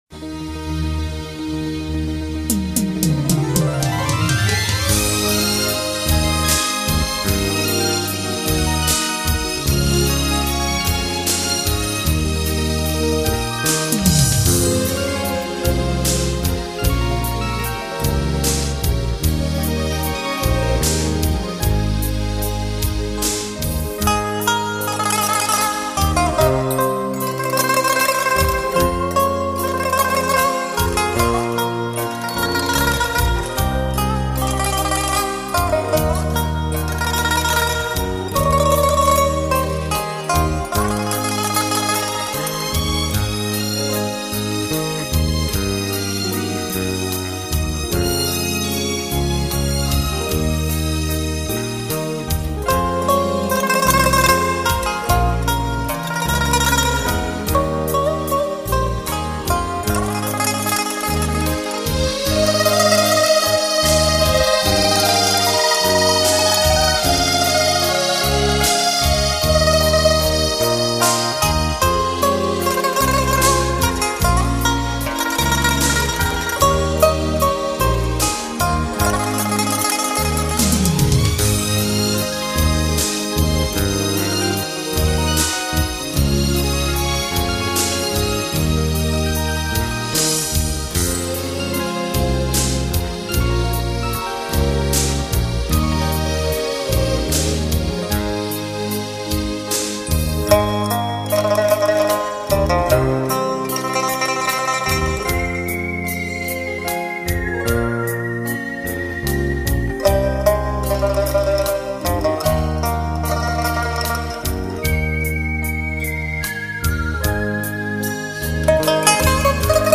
琵琶